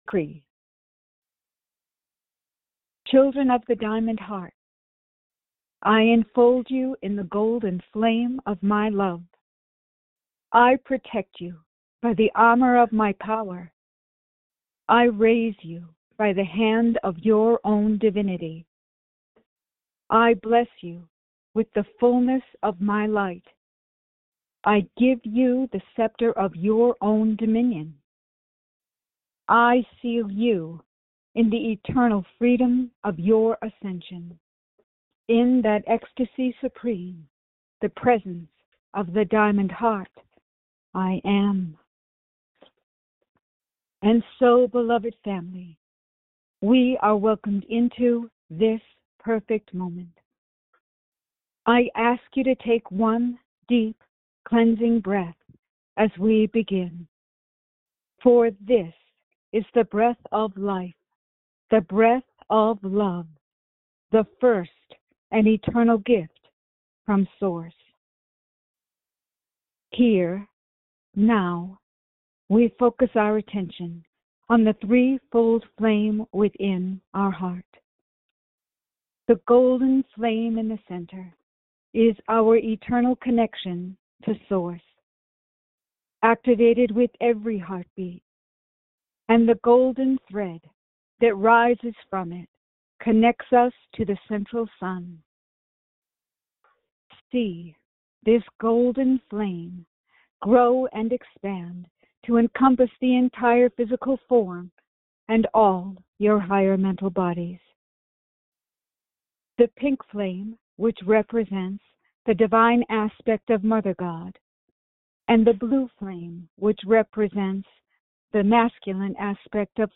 Audio Recording Meditation – Minute (00:00) Follow along in meditation with master Saint Germain.